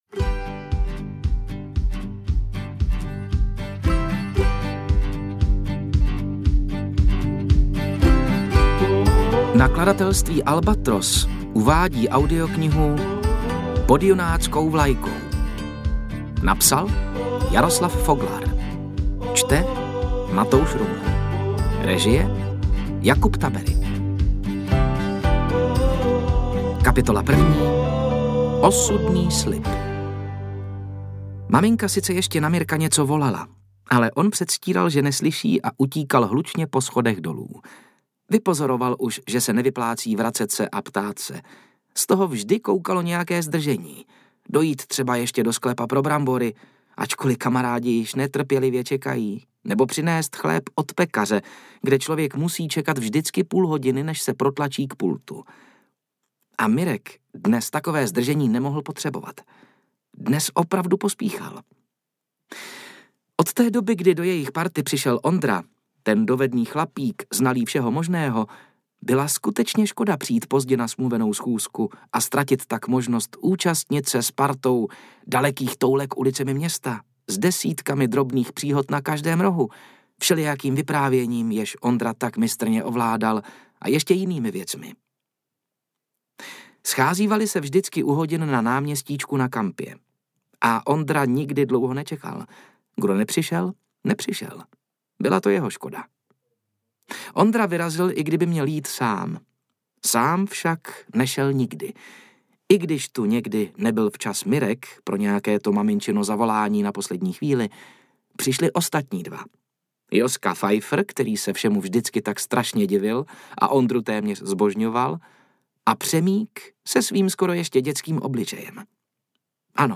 Interpret:  Matouš Ruml